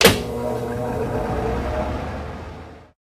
fan_start_01.ogg